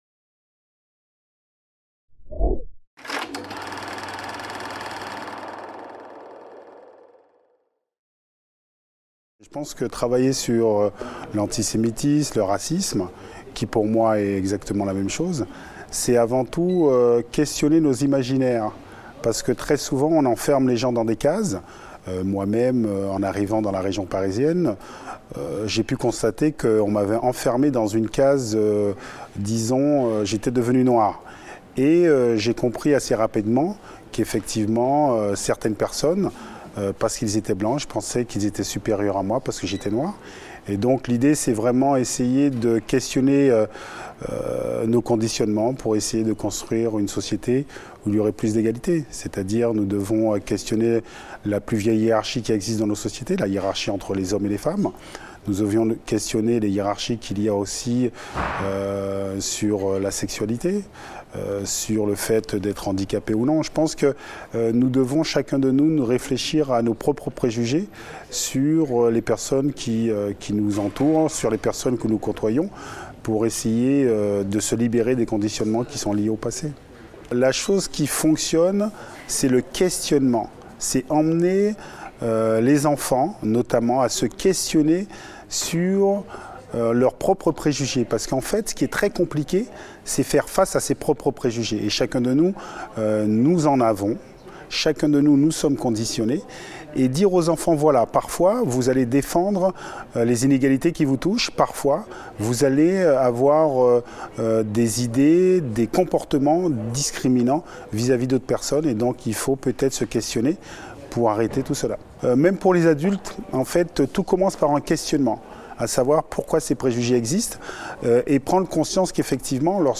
Interview de Lilian THURAM (Pour le lancement du séminaire sur le racisme et l'antisémitisme - PIRA) | Canal U